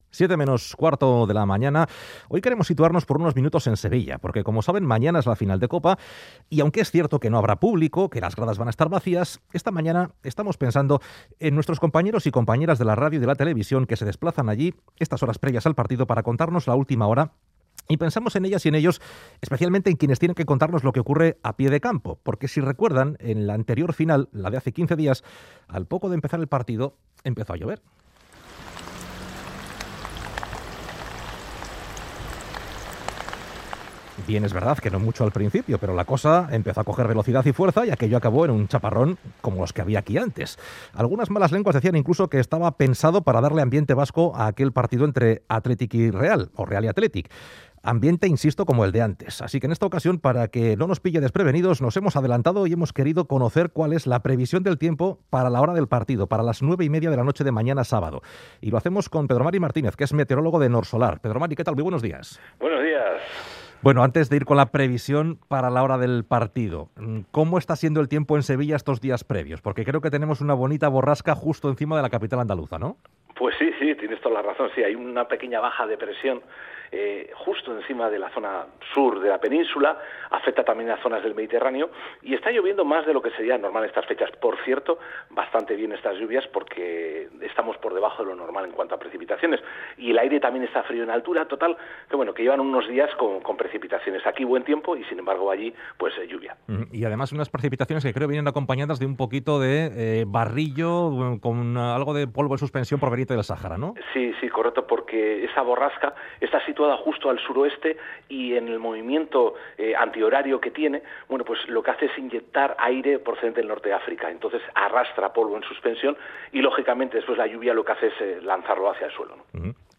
Previsión meteorológica para la final de COPA Athletic-Barça